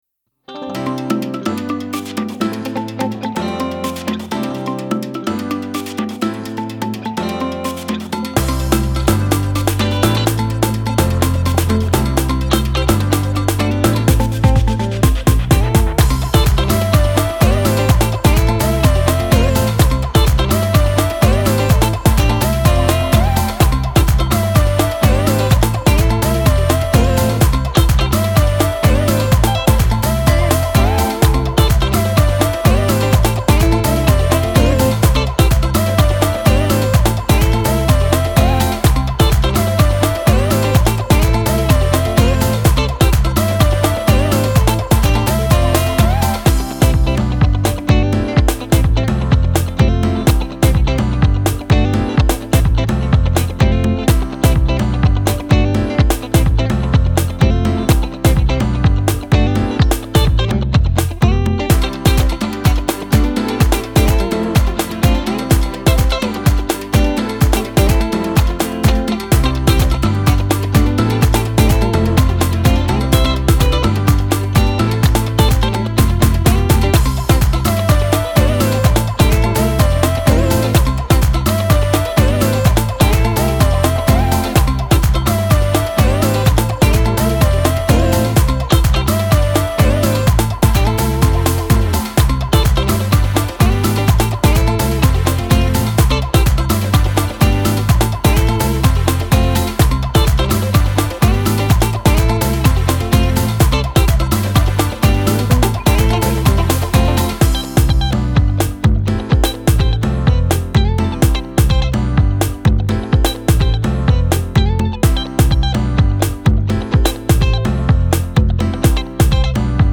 Saint Lucian music
Genre: Soca